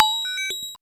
MOOGHAT36.wav